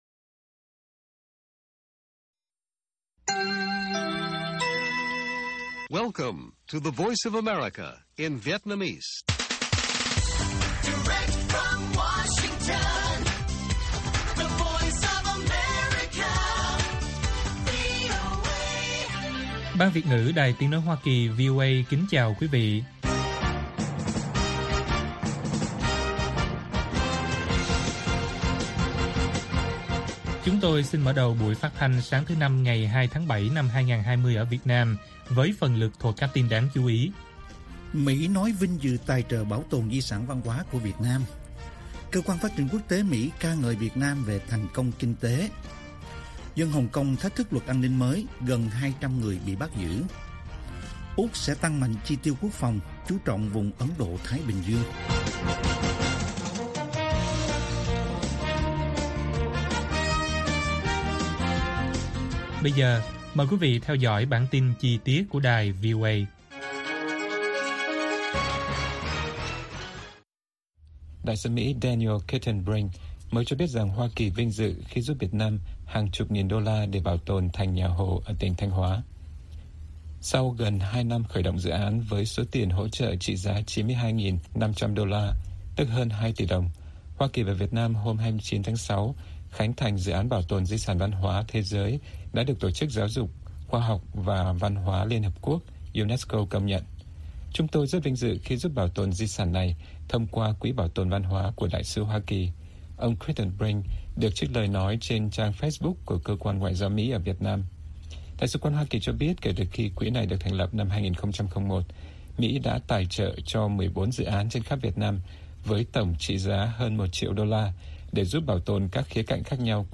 Bản tin VOA ngày 2/7/2020